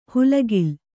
♪ hulagil